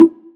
Bongo [3].wav